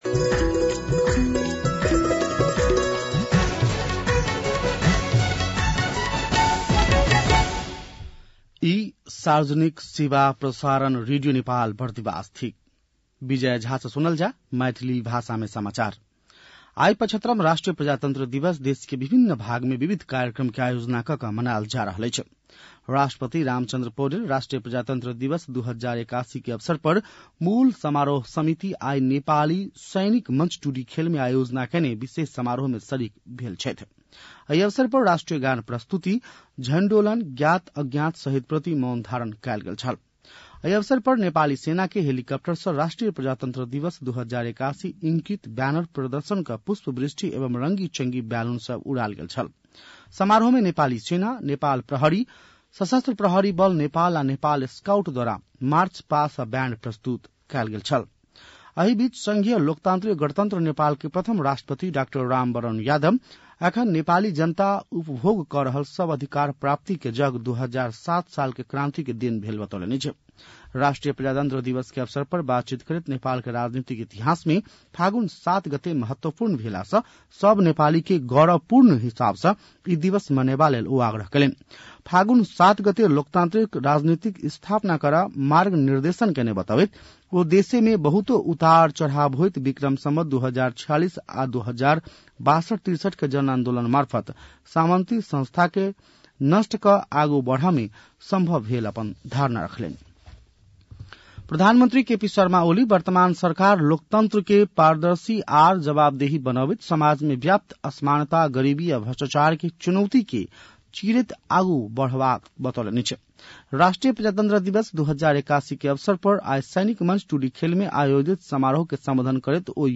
मैथिली भाषामा समाचार : ८ फागुन , २०८१